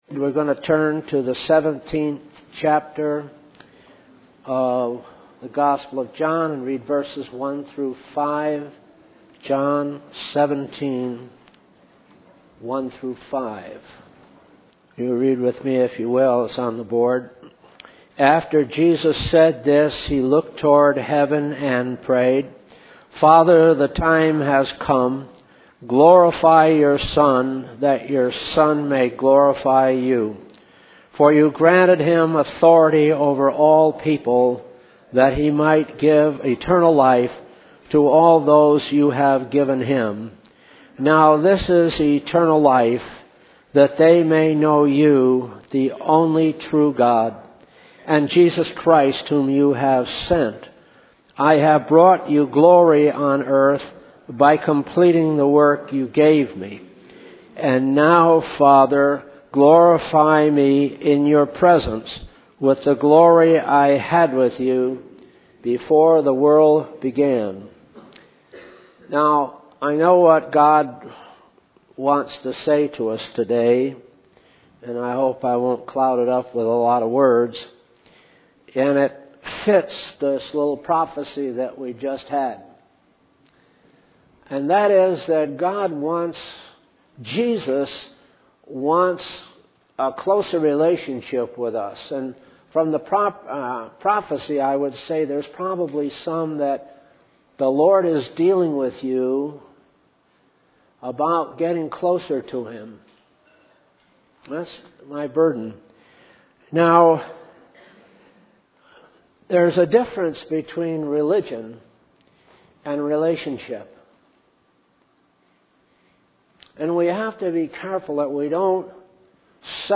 In this sermon, the preacher emphasizes the importance of living for Christ rather than living for oneself.